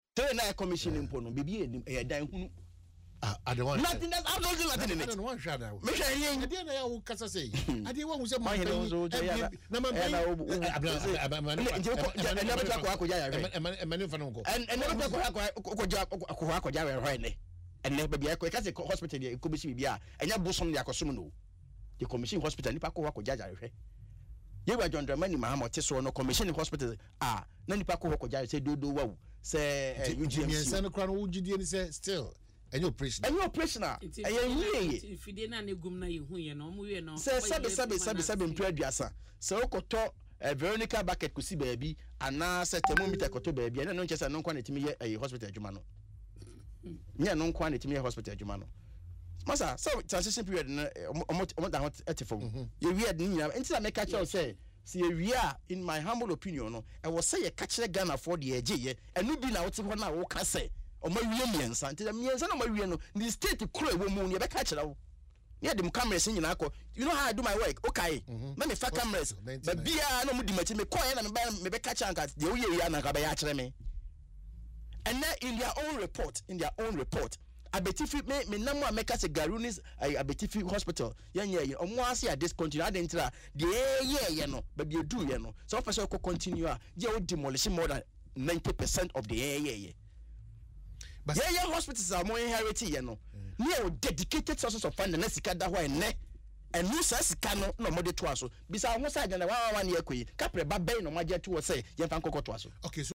However, the Ranking Member Parliament Health Committee on Asempa FM Ekosii Sen programme indicated that, the hospitals are incomplete and not operational.